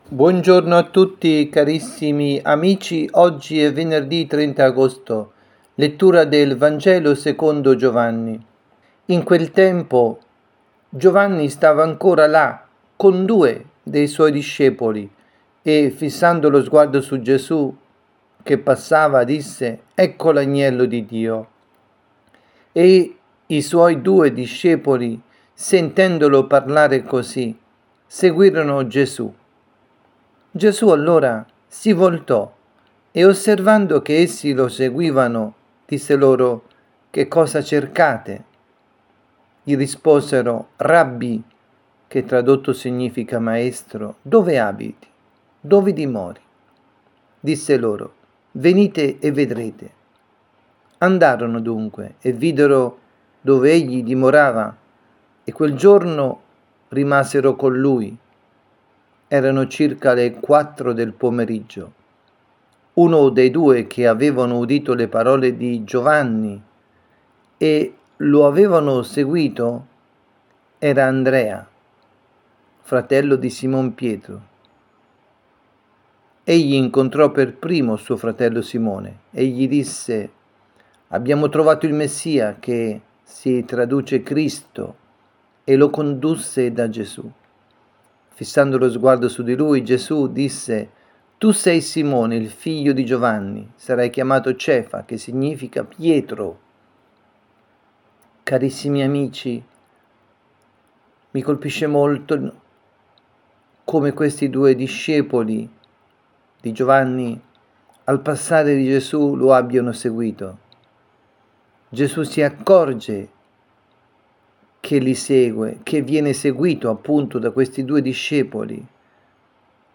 Catechesi
dalla Parrocchia Santa Rita – Milano –  Vangelo del rito Ambrosiano – Giovanni 1, 35-42.